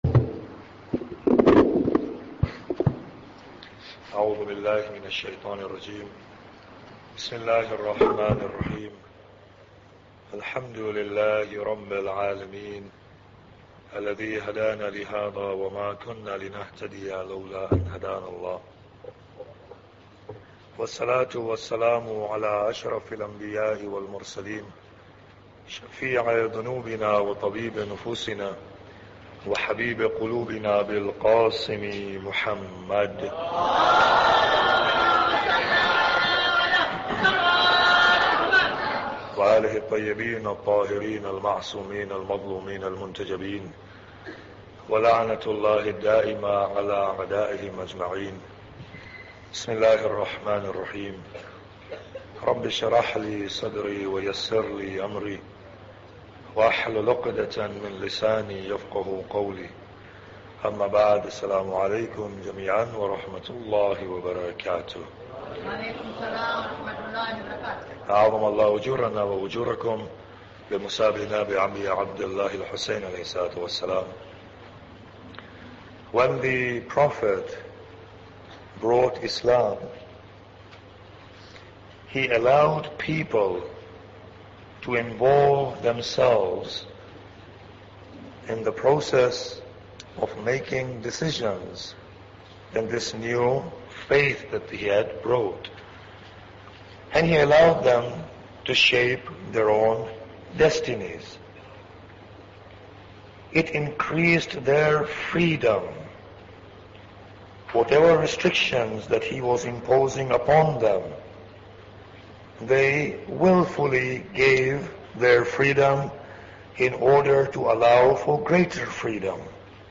Muharram Lecture 5